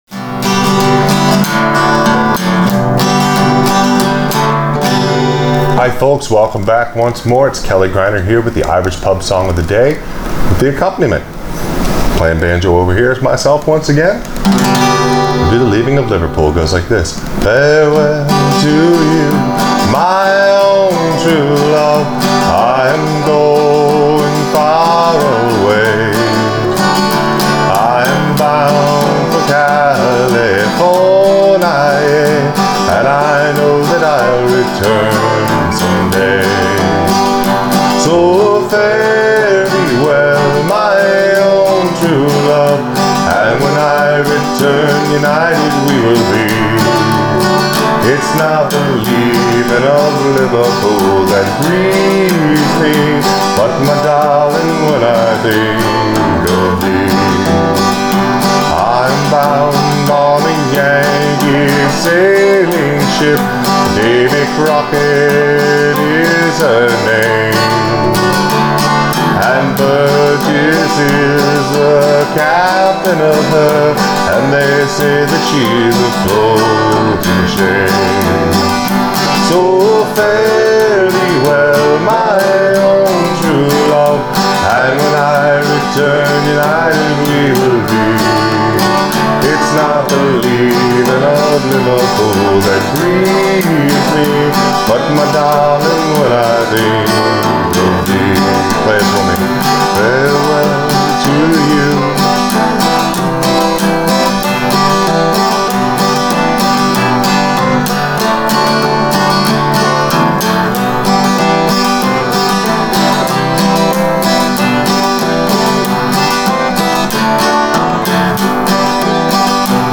Irish Pub Song Of The Day – Leaving Of Liverpool Accompaniment for Frailing Banjo
I got a better set of headphones so I could hear what I had done earlier on guitar instead of guessing my way through the song!